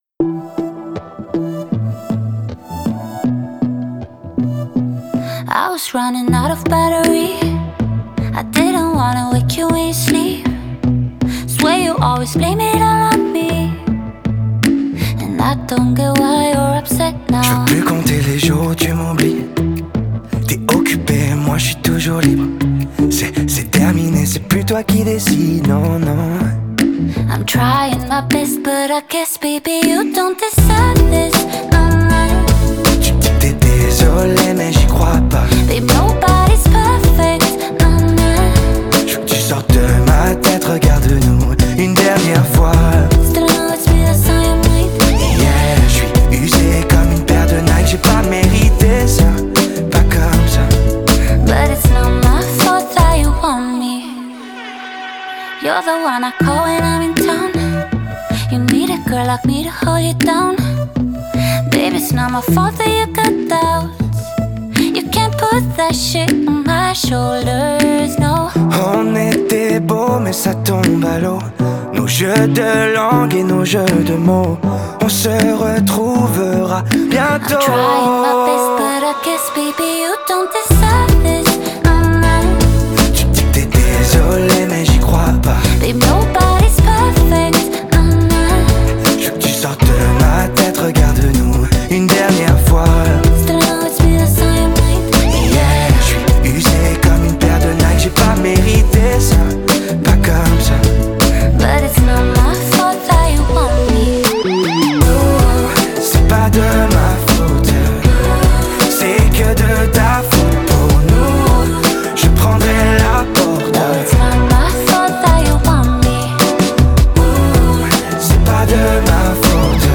это яркий поп-трек с элементами R&B